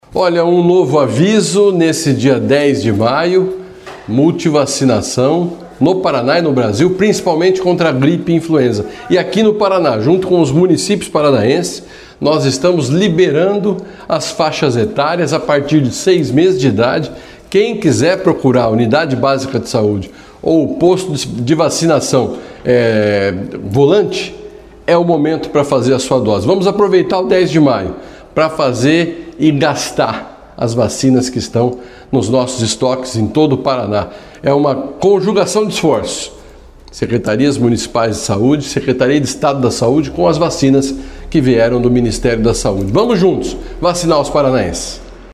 Nesse sábado (10), as secretarias de Saúde de todo o Paraná realizam o Dia D de vacinação contra a gripe e um dia de multivacinação. Em Maringá, os postos de saúde não estarão abertos porque a cidade já promoveu o Dia D. O secretário de Saúde do Paraná, Beto Preto, diz que a vacina está liberada para todos os públicos e convoca a população a comparecer aos postos de saúde.